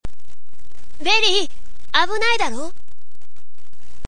１４歳/男性
サンプルボイス